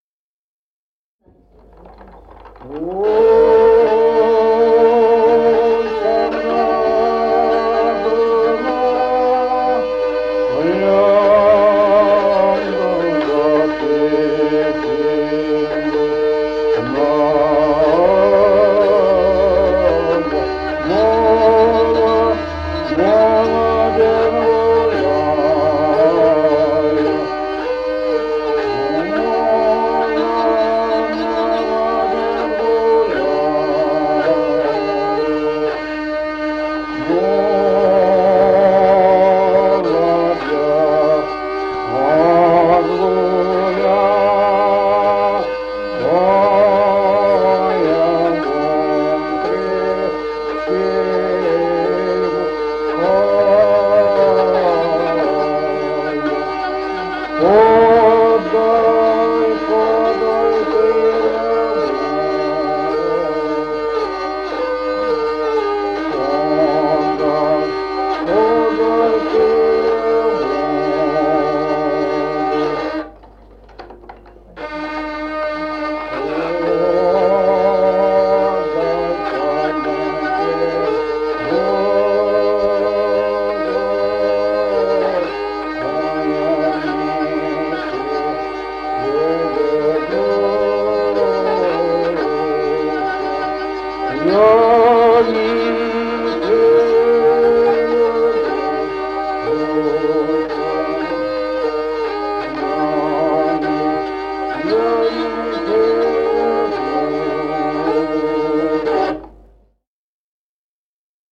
Музыкальный фольклор села Мишковка «Ой, там на Дунае», лирическая.